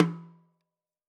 Index of /musicradar/Snares/Sonor Force 3000
CYCdh_SonSnrOff-06.wav